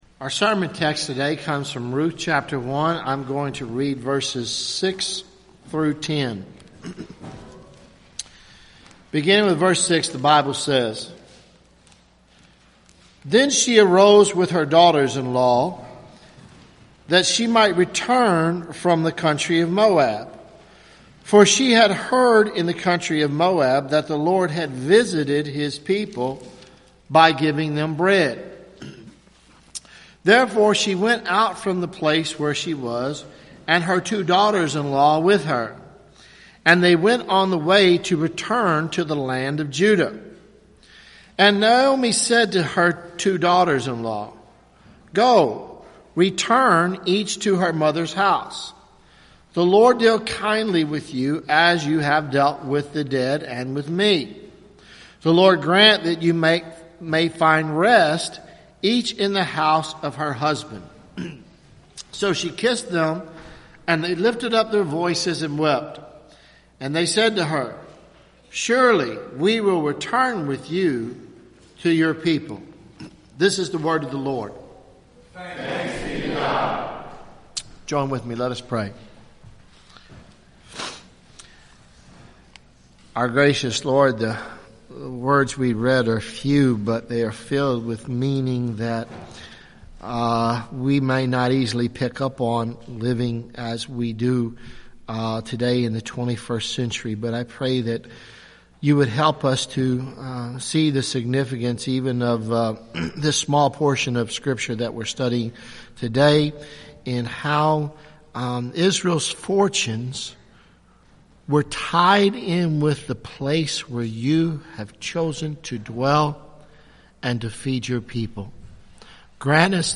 Sermons Nov 01 2015 “The Return
1-November-2015-sermon.mp3